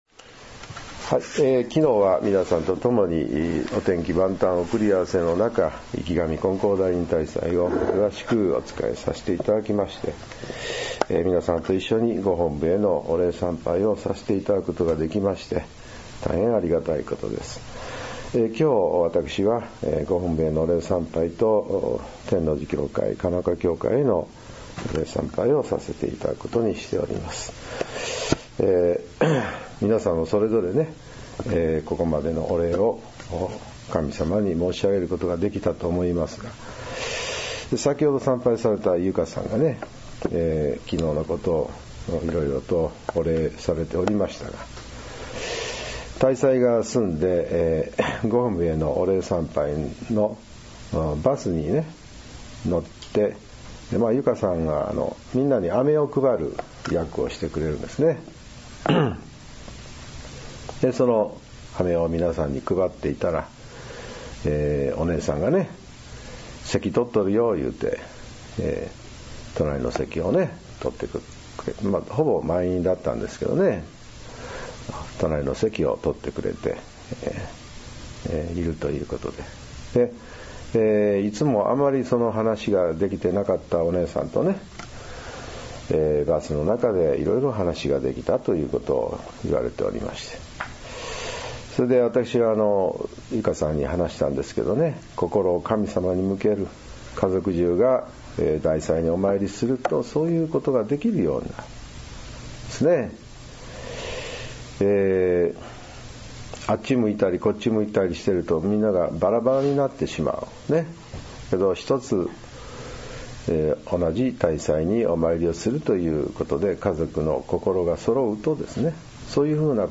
朝の勢祈念時のお話を音声ブログとして、聞くことができます。
今回は、教会長による「きょうからの信心」というお話です。